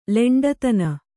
♪ leṇḍatana